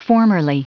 Prononciation du mot formerly en anglais (fichier audio)
Prononciation du mot : formerly